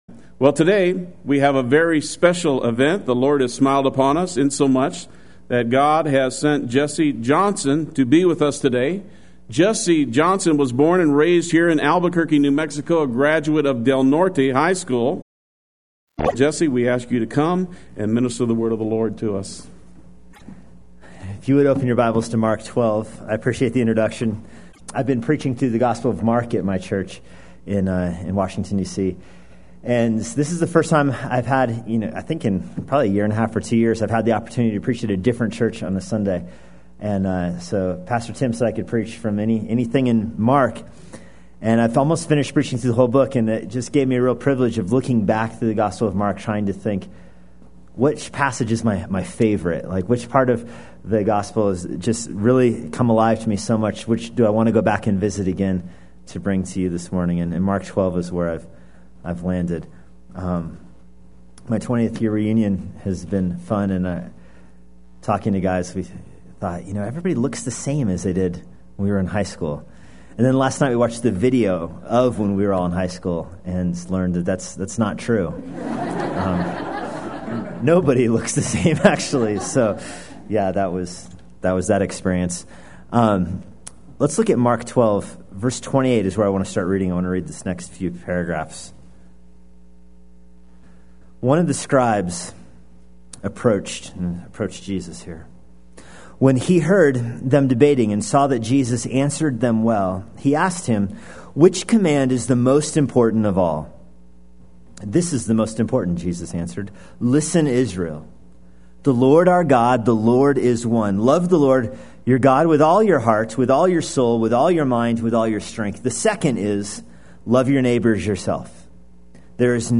Play Sermon Get HCF Teaching Automatically.
When Good Isn’t Good Enough Sunday Worship